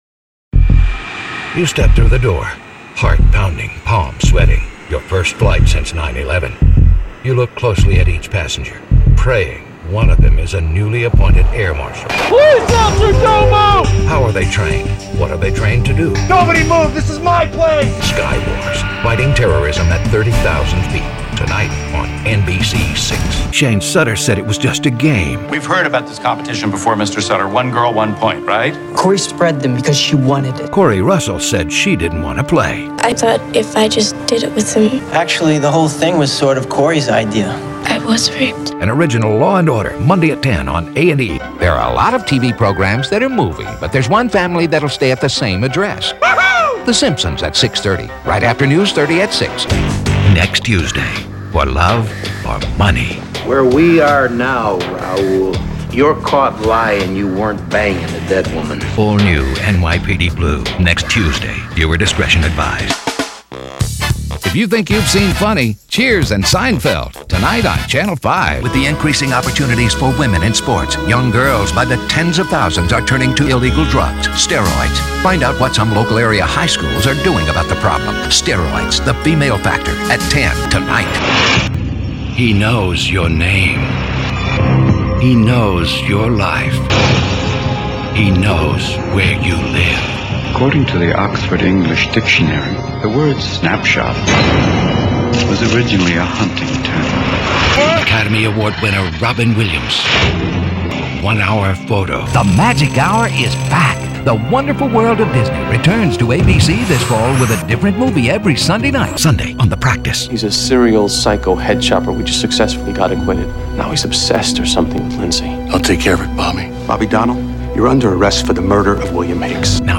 Middle Aged
Quiet in-house audio booth. Classic 1981 Neumann U-87. Symetrix 528E processor.